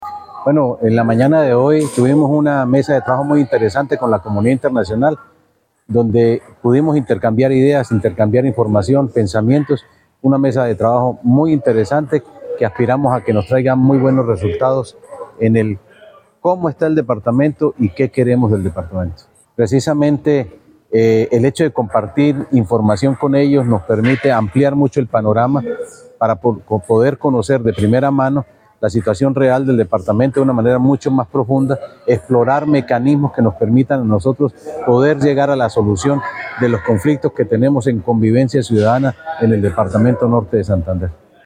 2. Audio de Jhonny Peñaranda, secretario de Gobierno